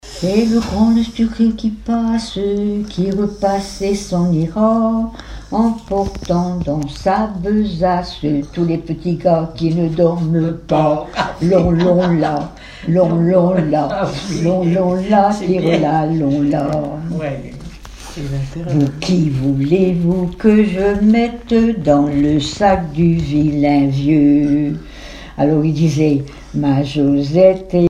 Mémoires et Patrimoines vivants - RaddO est une base de données d'archives iconographiques et sonores.
Chansons populaires
Pièce musicale inédite